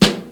• 2000s Boom Snare Drum G Key 24.wav
Royality free acoustic snare sound tuned to the G note. Loudest frequency: 1834Hz